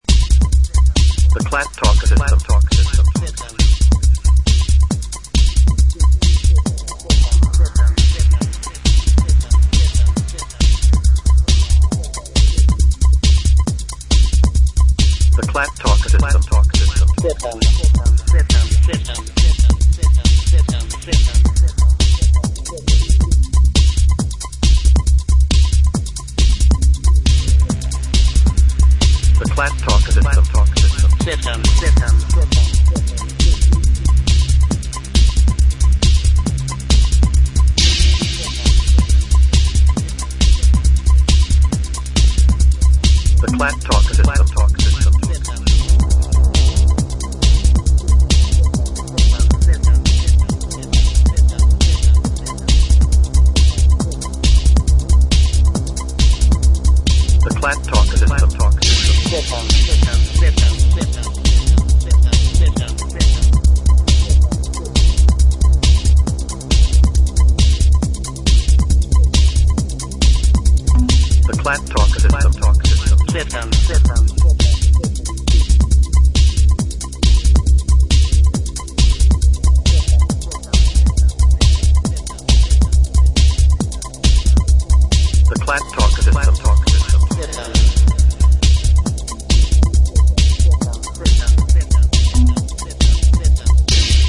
the tracky, tripping metallic thump
droning, mesmerising musings